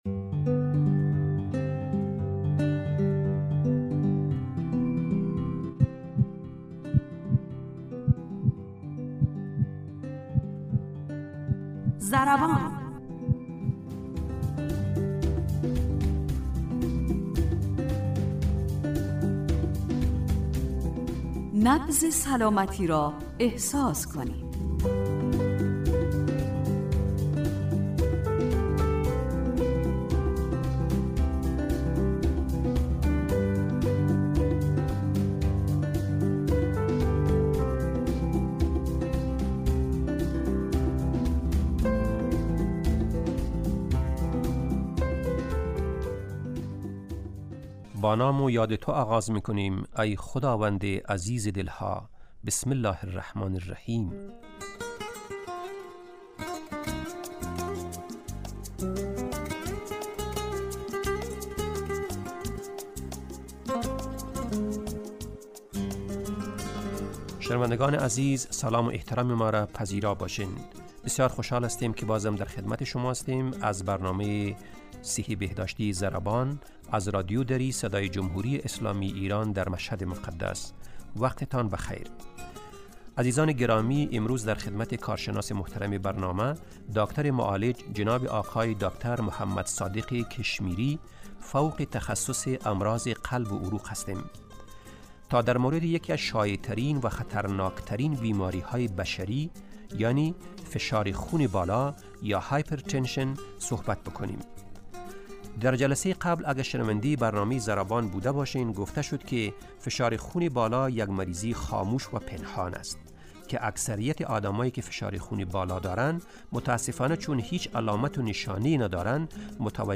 برنامه ضربان، برنامه ای صحی و بهداشتی است که با استفاده از تجربیات کارشناسان حوزه بهداشت و سلامت و استادان دانشگاه، سعی دارد مهمترین و شایع ترین مشکلات صحی...